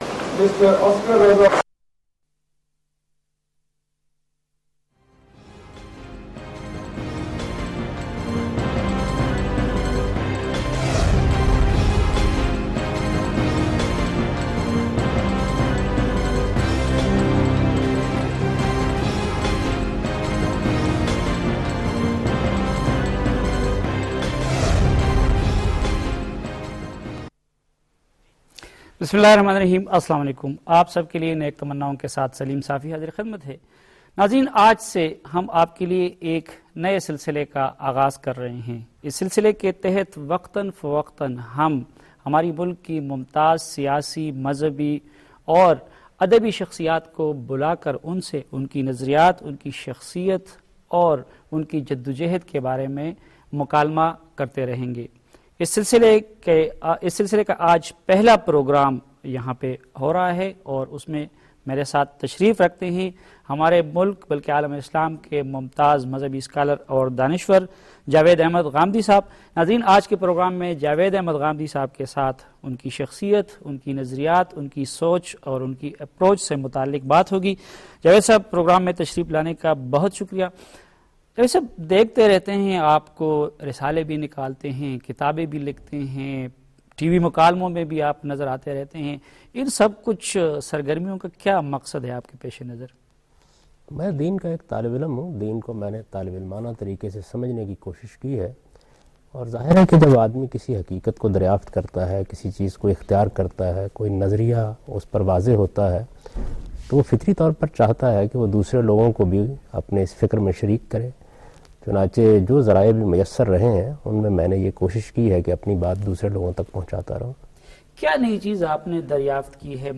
In this program Saleem Safi discusses Democracy in the Muslim World with Javed Ahmad Ghamidi